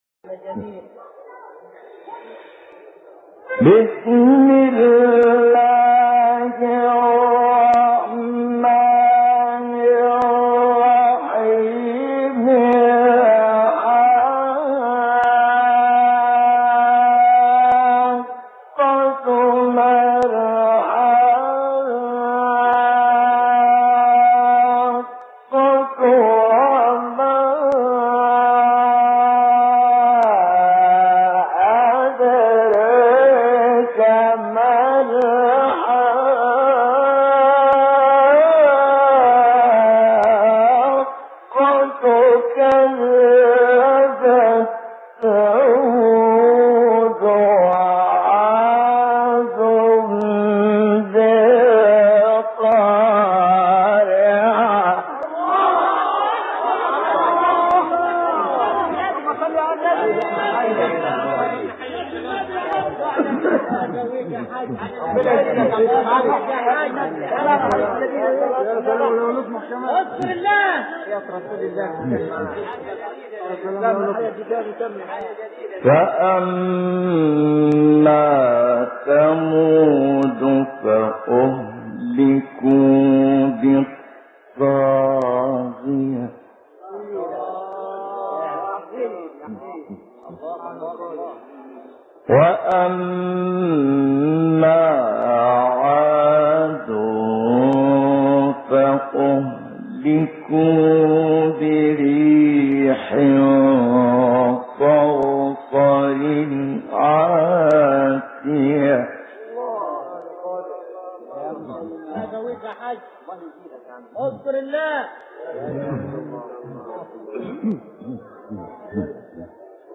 سوره حاقه ، تلاوت قرآن